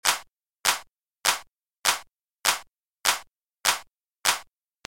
Here’s my clap sound with the compressor bypassed: